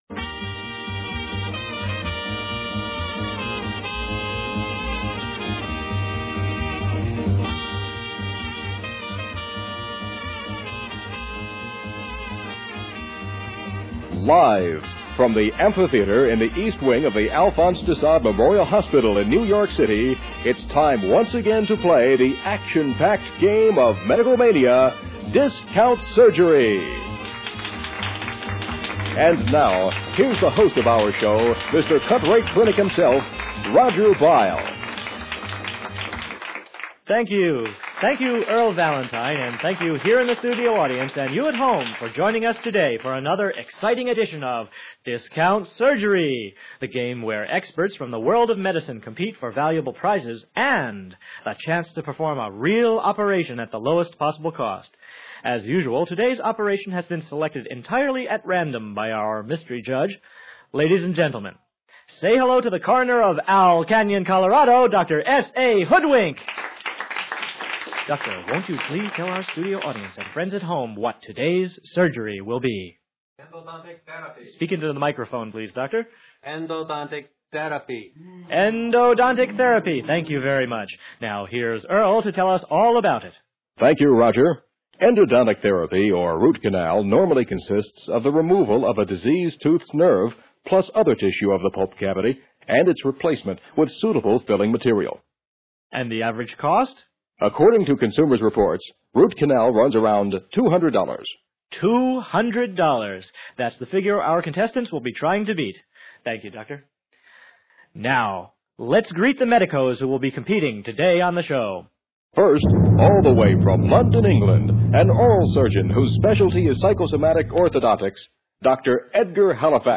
In the meantime, here are some lo-fi versions of the radio programs... these were done in 1978.